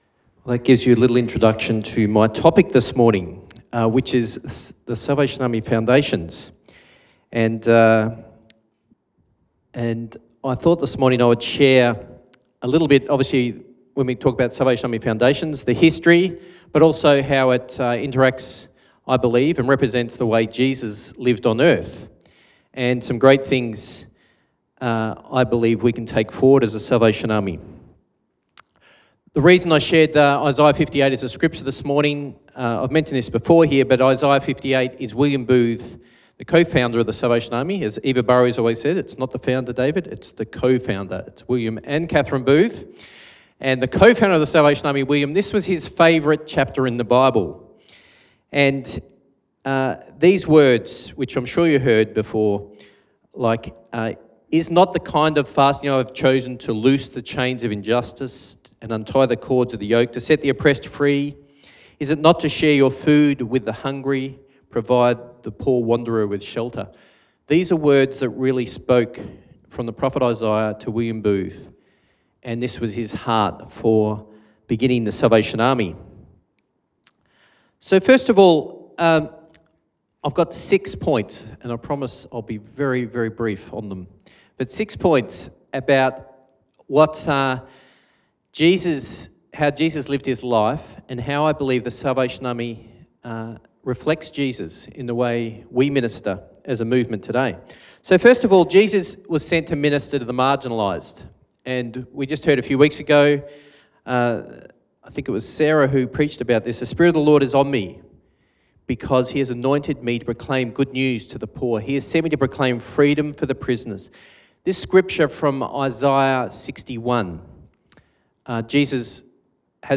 Sermon Podcasts Foundations